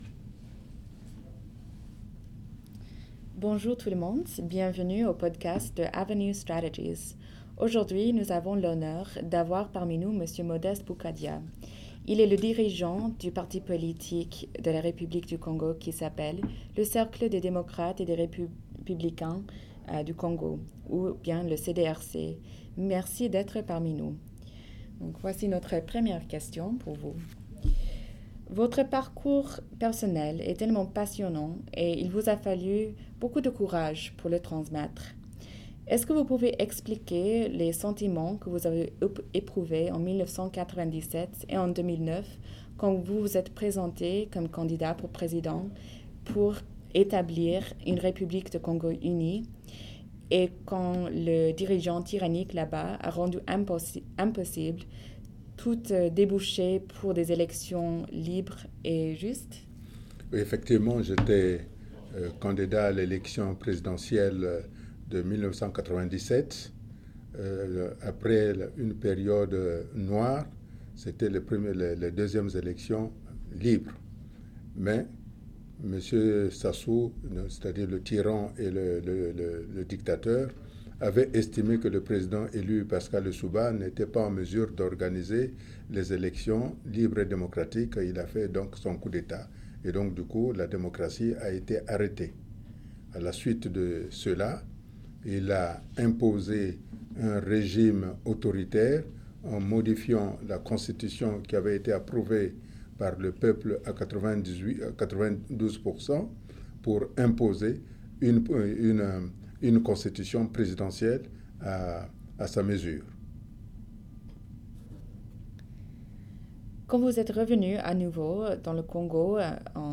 Audio de l’interview
The interview took place on March 9, 2018 in Washington DC, USA, during his official working visit where he met various members of the Congres, the Senate and other governmental departments and agencies.